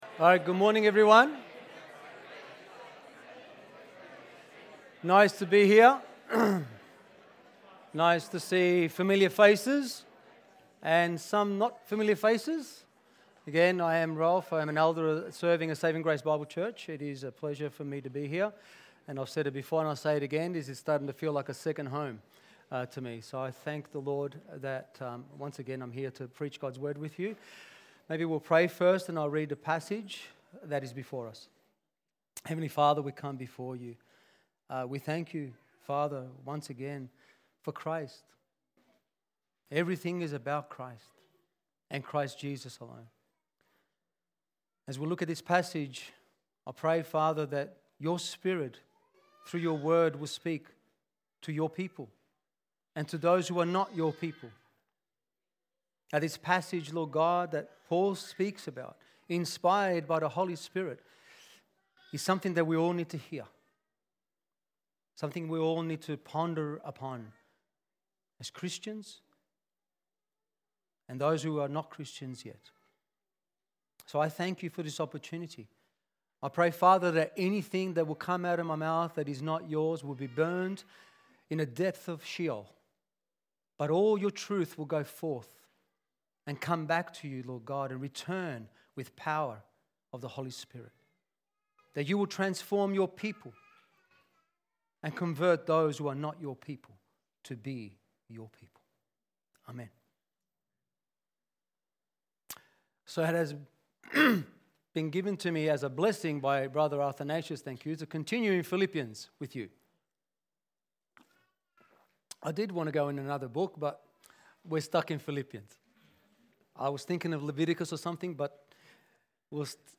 Church-Sermon-130425.mp3